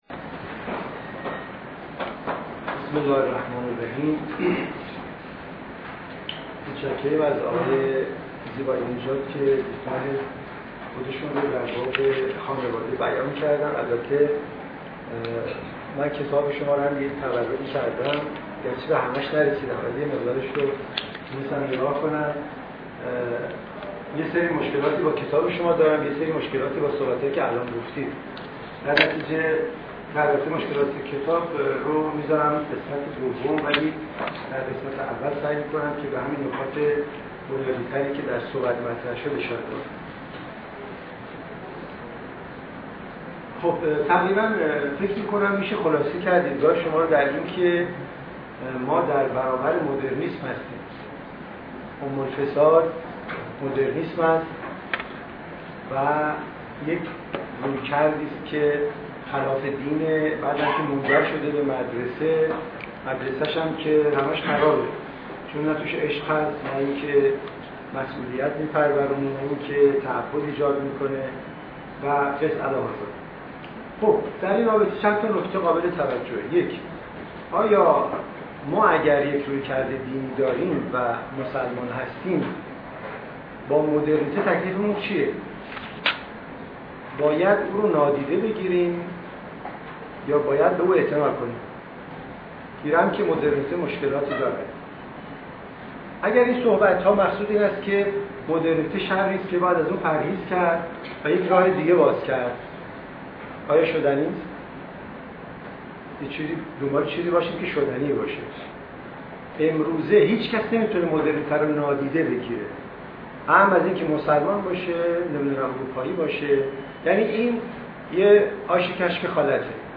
سخنرانی
در دانشکده روانشناسی دانشگاه تهران